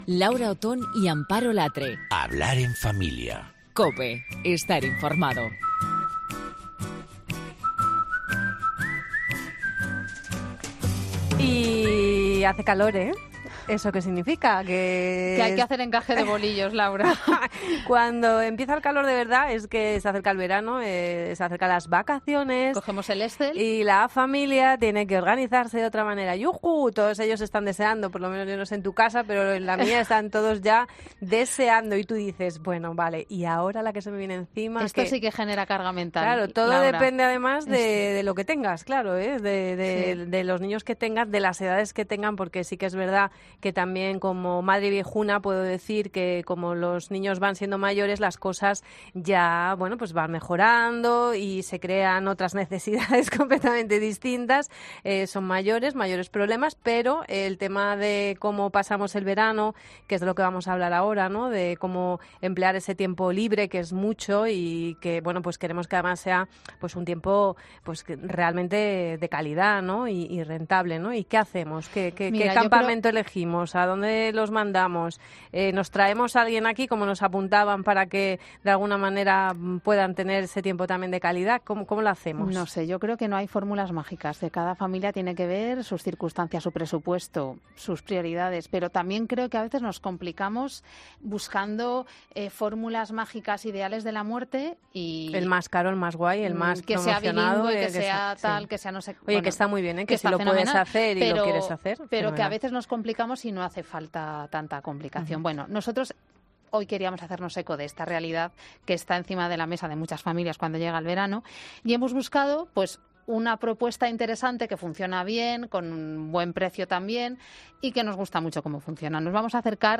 Si quieres conocer el proyecto que mueve a más de 15 mil niños y jóvenes en toda España no dejes de escuchar esta entrevista.